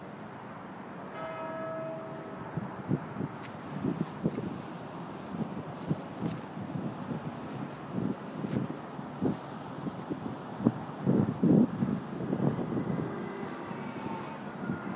Bell tolling at Westminster abbey